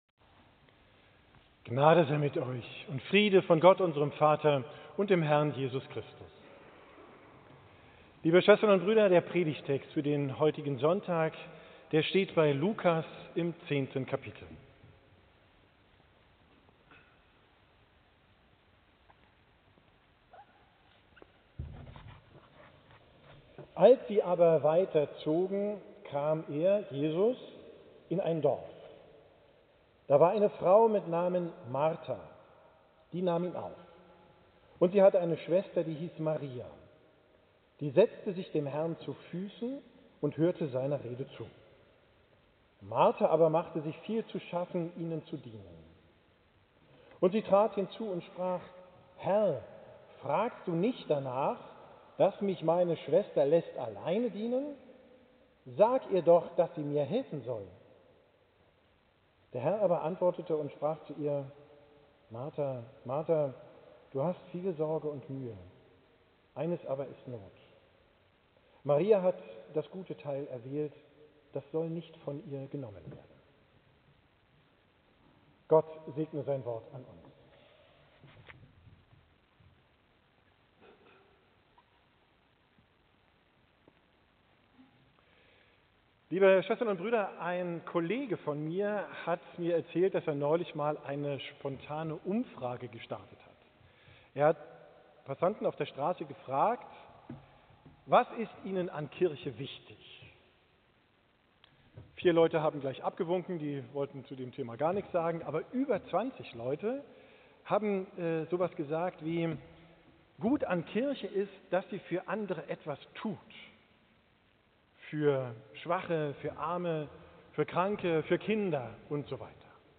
Wie Marta Frieden findet - Neuigkeiten, Nachrichten vom Pastor und Veranstaltungen - Hauptkirche Altona | St. Trinitatis